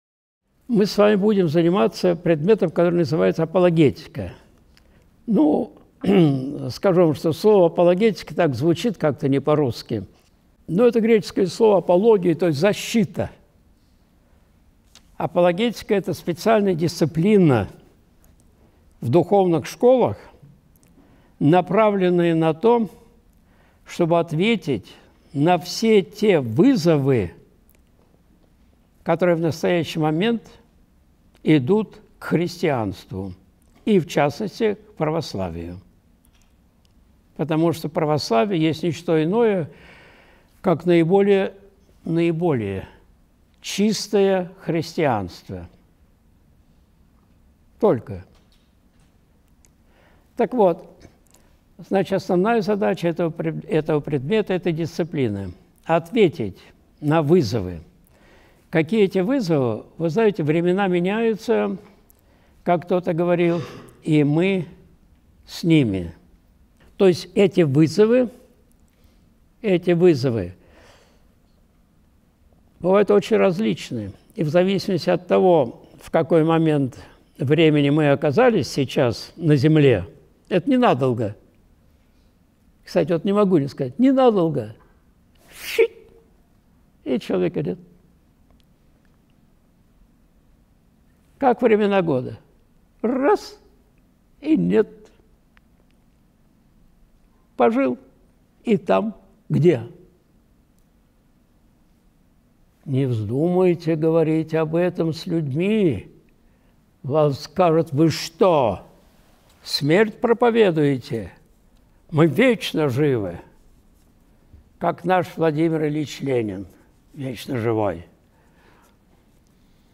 Вводная лекция (Апологетика, 23.01.2026)
Видеолекции протоиерея Алексея Осипова